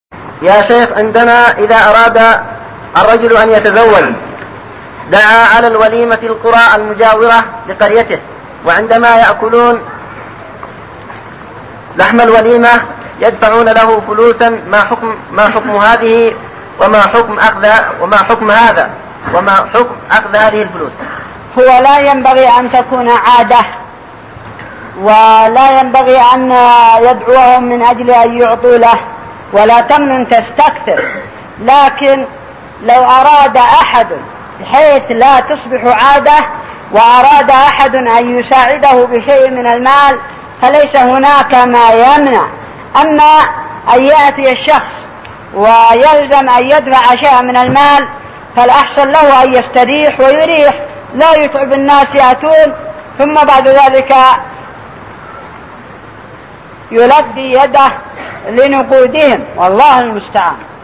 دفع المال في الوليمة للزوج | فتاوى الشيخ مقبل بن هادي الوادعي رحمه الله